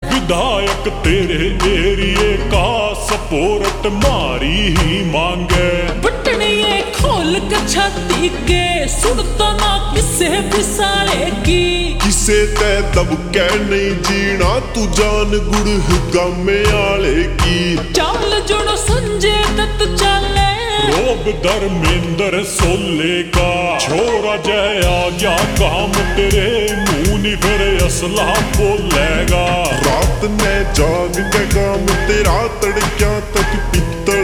Haryanvi Songs
Slow Reverb Version
• Simple and Lofi sound
• Crisp and clear sound